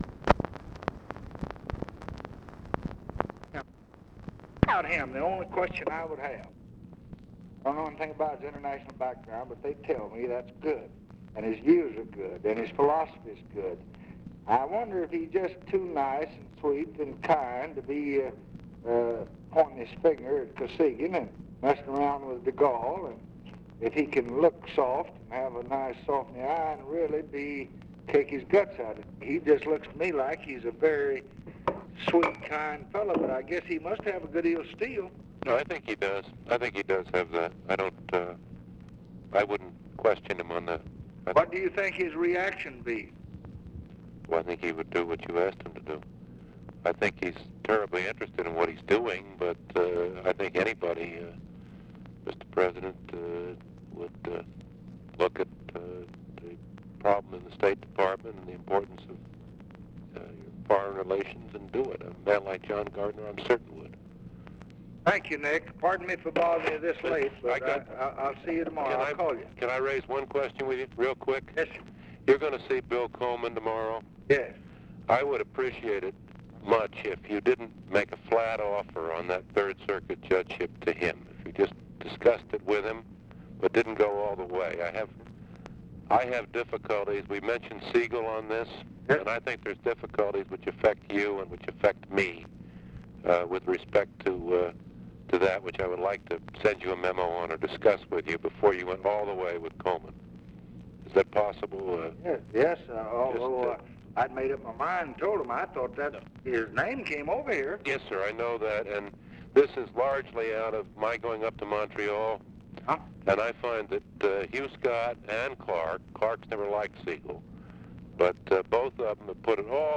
Conversation with NICHOLAS KATZENBACH, August 11, 1966
Secret White House Tapes